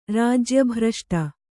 ♪ rājya bhraṣṭa